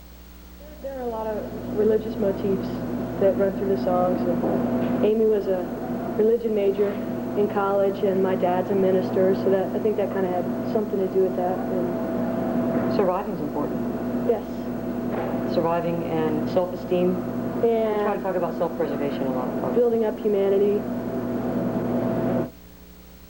04. interview (0:23)